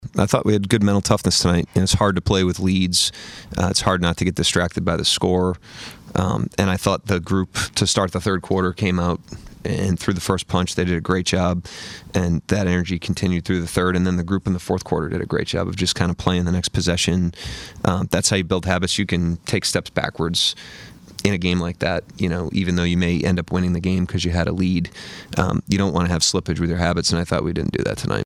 Head coach Mark Daigneault postgame.